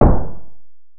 collision.wav